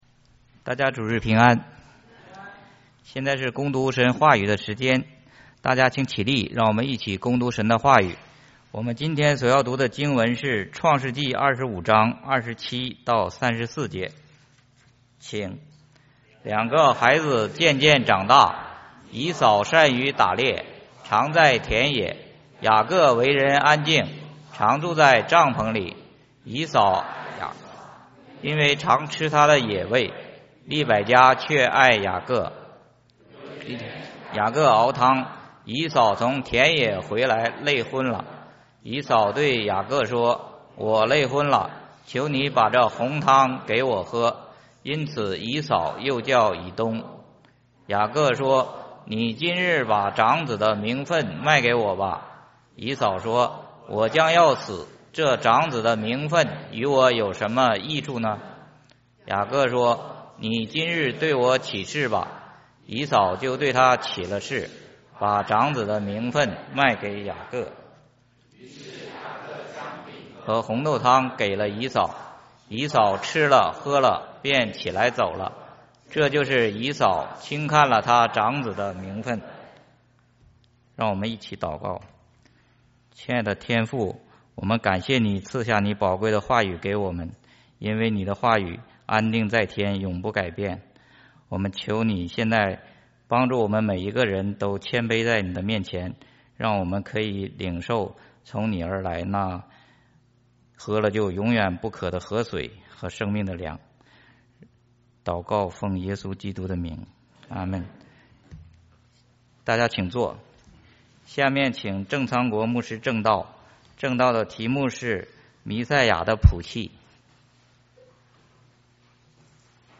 2017 主日證道 | Series | Chinese Baptist Church of West Los Angeles